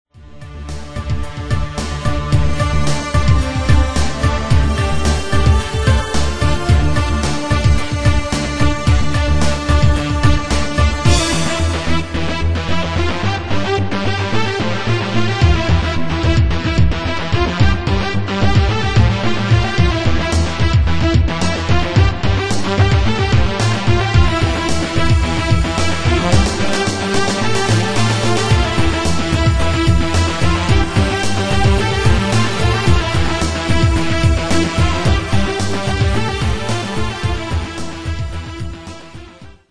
You should turn down the reverb usage a bit, and it's a bit lacking in the lower frequencies, IMO the bass should be using little to no reverb at all.
very goodm but you should have more use of hihats and other rythmic samples the drum's  aren't filled enough, otherwise good work!